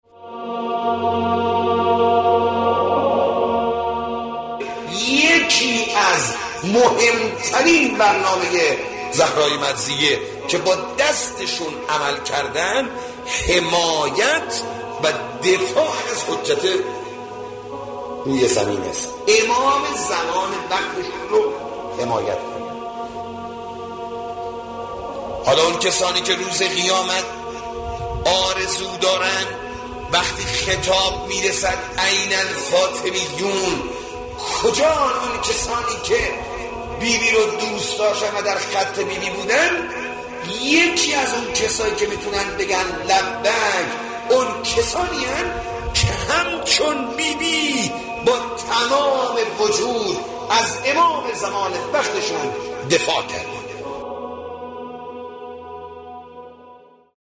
شهادت حضرت زهرا(س) آموزه‌ای متعالی در باب دفاع از آرمان‌های دینی و ارزش‌های اسلام است.ایکنا به مناسبت ایام سوگواری شهادت دخت گرامی آخرین پیام‌آور نور و رحمت، مجموعه‌ای از سخنرانی اساتید اخلاق کشور درباره شهادت ام ابیها(س) با عنوان «ذکر خیر ماه» منتشر می‌کند.
، پادکست مذهبی